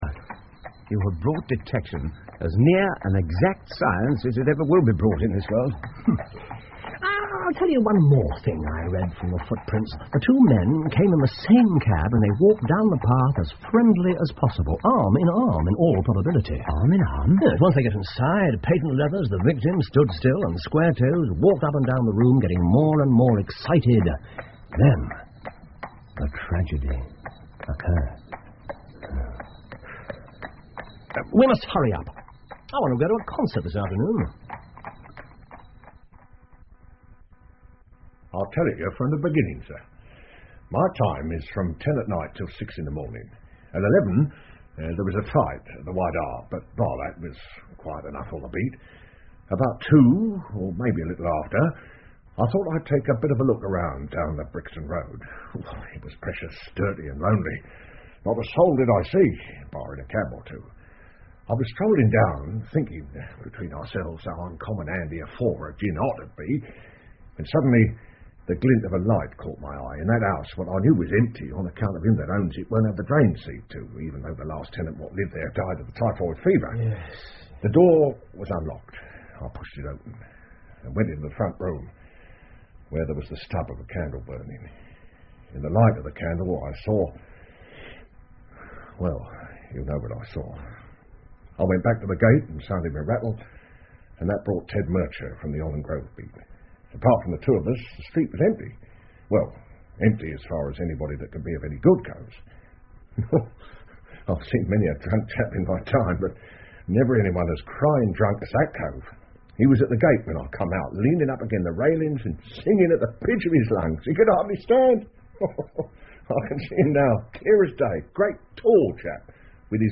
福尔摩斯广播剧 A Study In Scarlet 血字的研究 9 听力文件下载—在线英语听力室